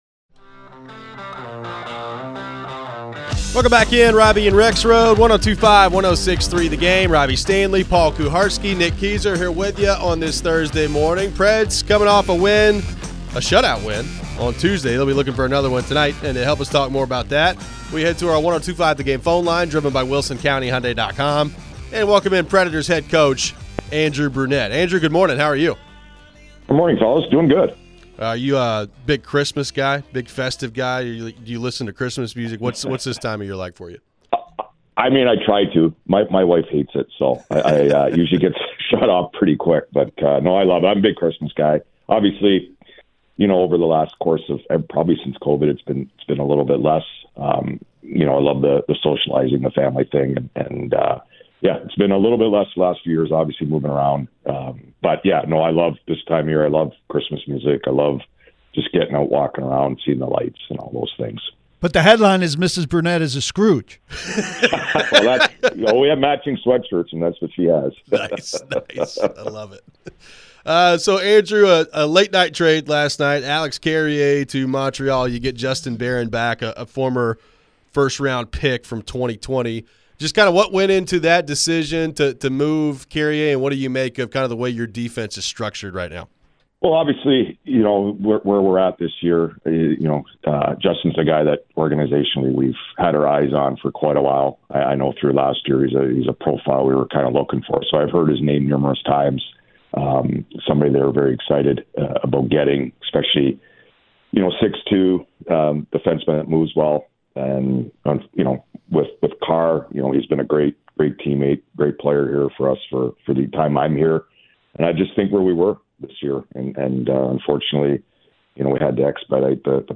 Andrew Brunette Interview (12-19-24)
Headliner Embed Embed code See more options Share Facebook X Subscribe Nashville Predators head coach Andrew Brunette joined the show ahead of Thursday's game against the Penguins. How does he feel about the trade for Justin Barron while surrendering Alex Carrier in the process? How does Brunette feel about the defensive corps & Stamkos playing center?